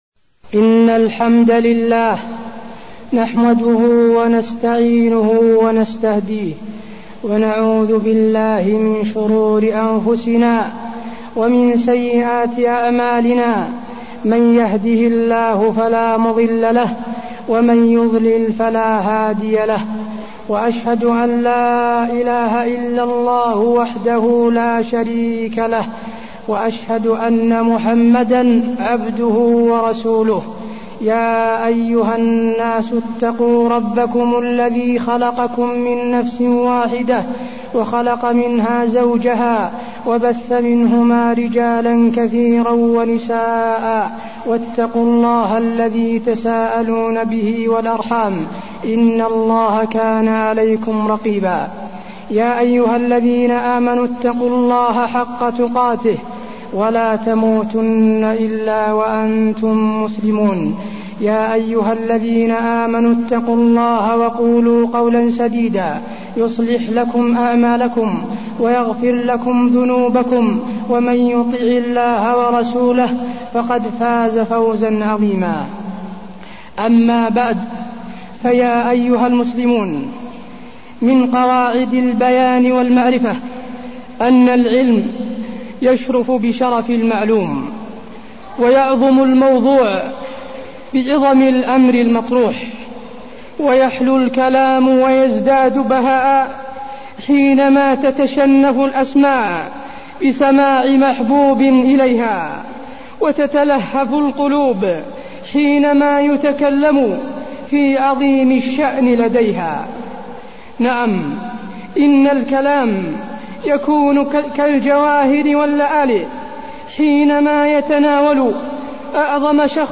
تاريخ النشر ٢٣ محرم ١٤٢١ هـ المكان: المسجد النبوي الشيخ: فضيلة الشيخ د. حسين بن عبدالعزيز آل الشيخ فضيلة الشيخ د. حسين بن عبدالعزيز آل الشيخ وجوب اتباع النبي صلى الله عليه وسلم